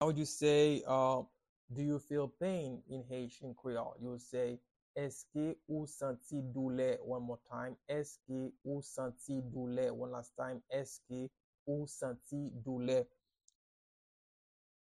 Pronunciation and Transcript:
Do-you-feel-pain-in-Haitian-Creole-–-Eske-ou-santi-doule-pronunciation-by-a-Haitian-teacher.mp3